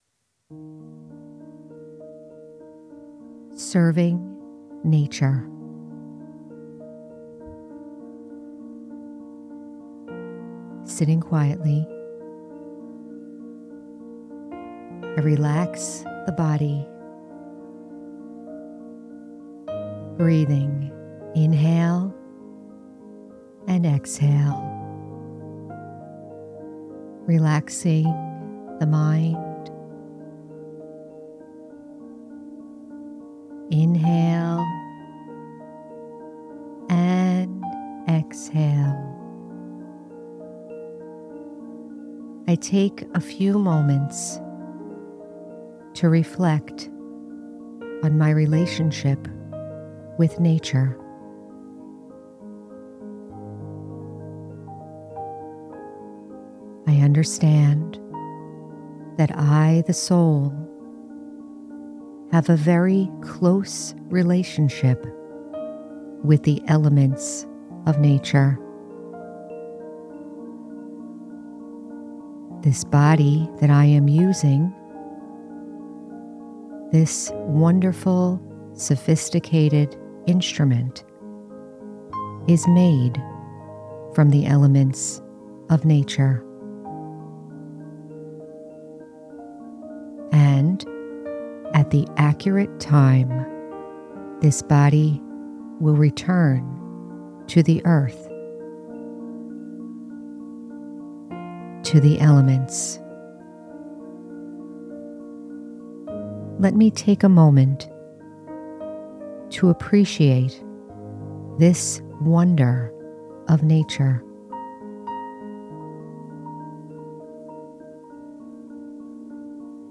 Serving Nature- Guided Meditation- The Spiritual American- Episode 165